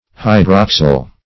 Hydroxyl \Hy*drox"yl\, n. [Hydro-, 2 + oxygen + -yl.] (Chem.)